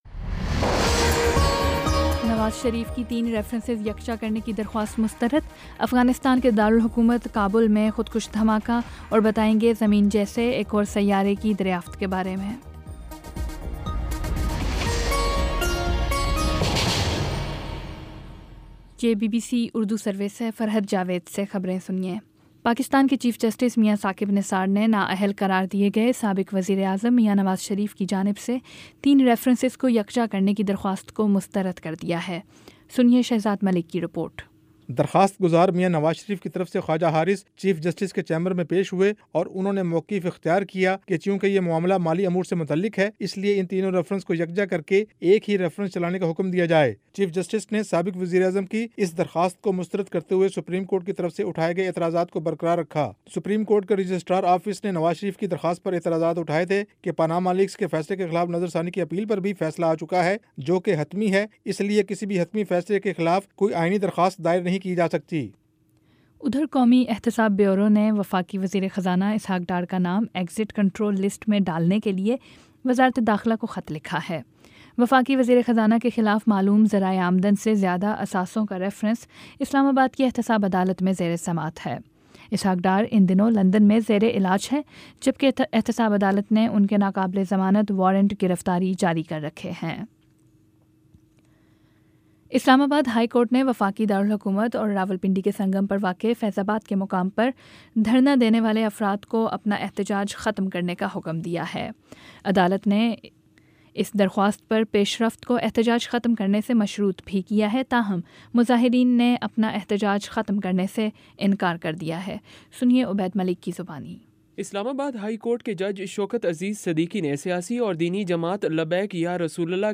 نومبر 16 : شام سات بجے کا نیوز بُلیٹن